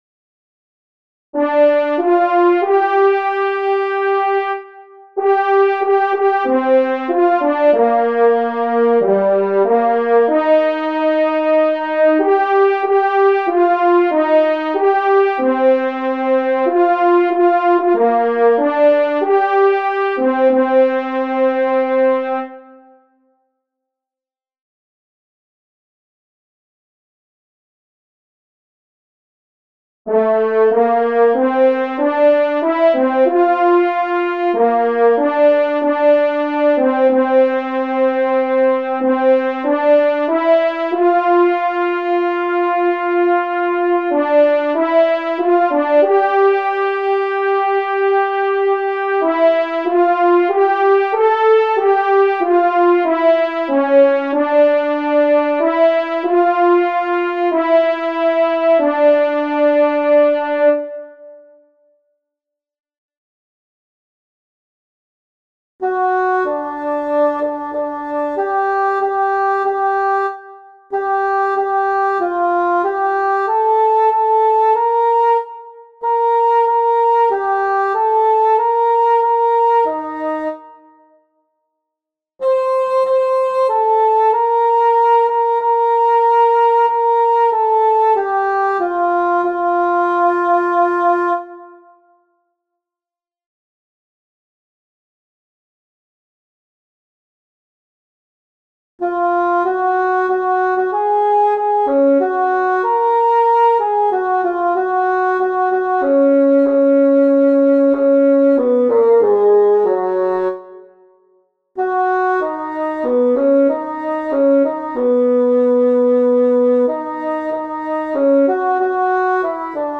Practice voice parts:
Help for Alto II singers:
Alto 2 helps Tenors and then sings Choir 2 Alto beginning on page 6
i_was_glad-alto2-vigil-2025.mp3